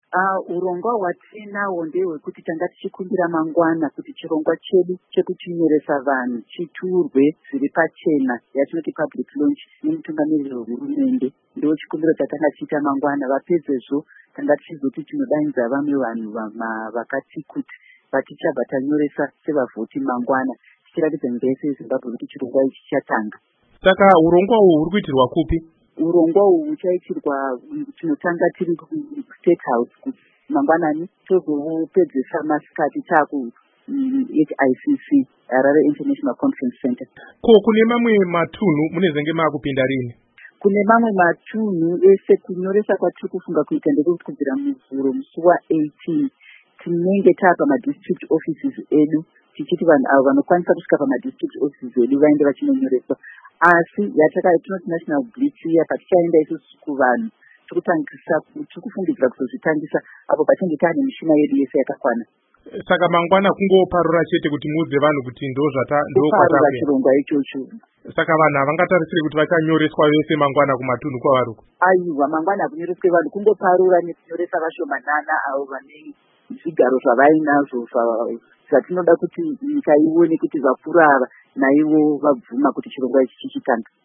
Hurukuro naAmai Rita Makarau